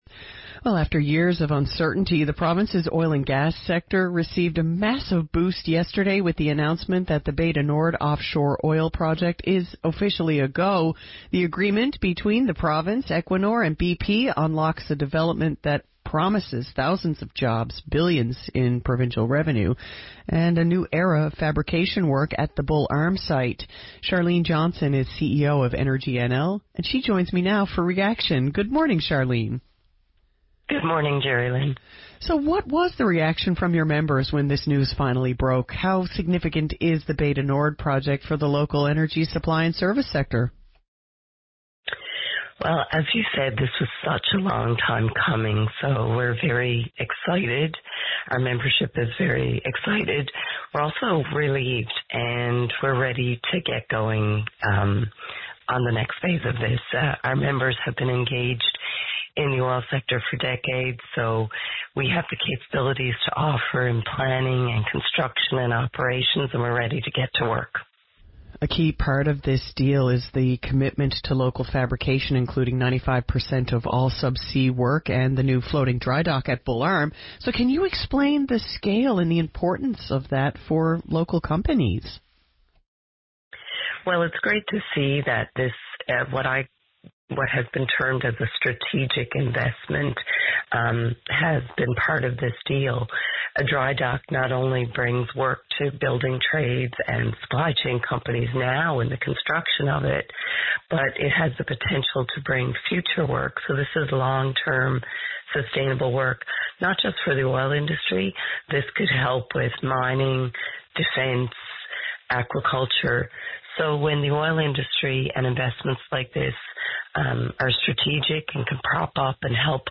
Energy NL CEO Speaks with VOCM Morning Show About Bay du Nord